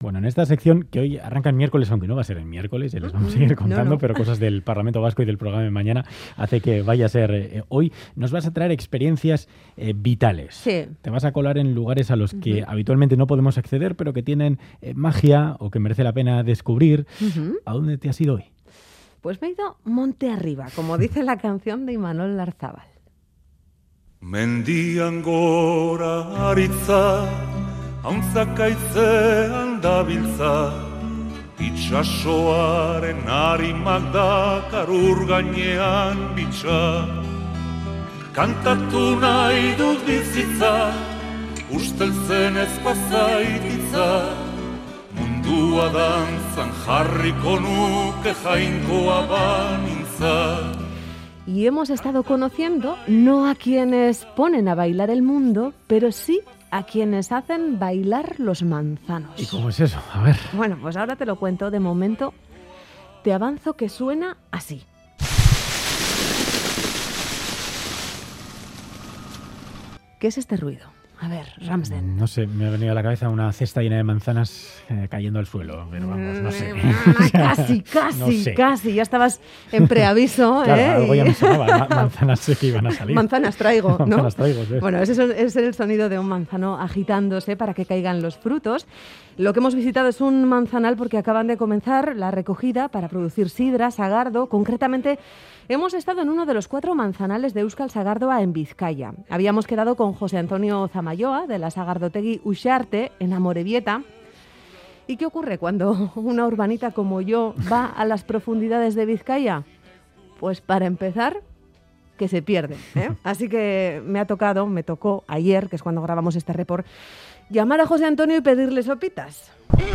"Boulevard" de Radio Euskadi asiste a la recogida de manzana para la elaboración de sidra en Amorebieta